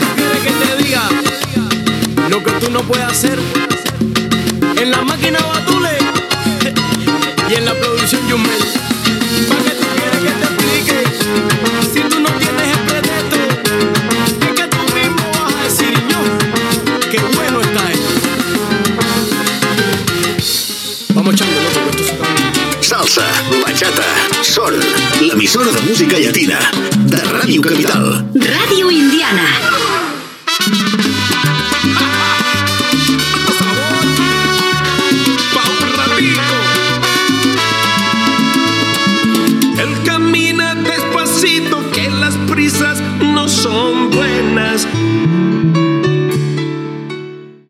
Tema musical, indicatiu i tema musical